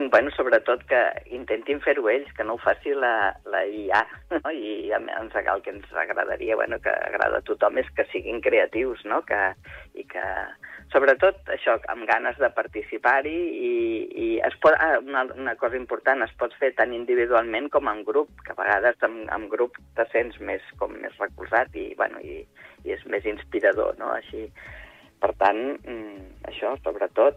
EntrevistesProgramesSupermatí